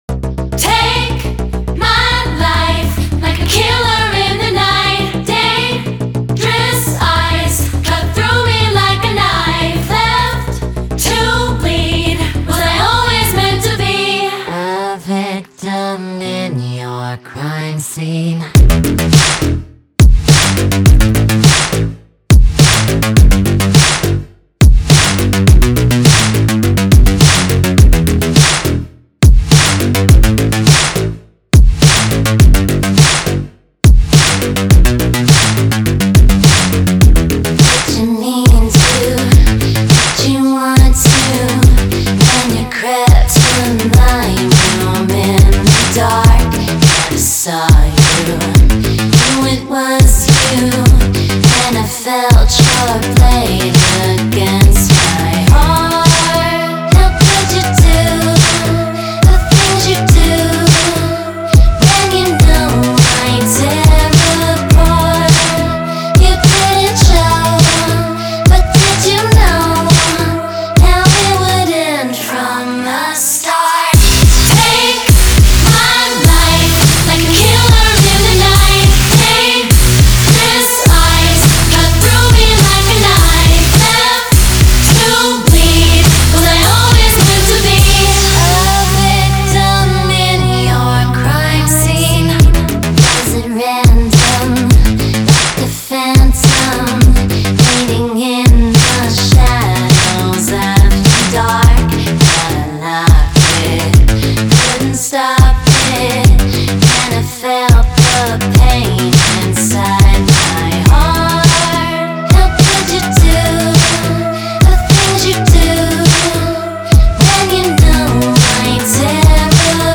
BPM104-104
Audio QualityPerfect (High Quality)
Dark Pop song for StepMania, ITGmania, Project Outfox
Full Length Song (not arcade length cut)